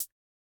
RDM_Raw_MT40-ClHat.wav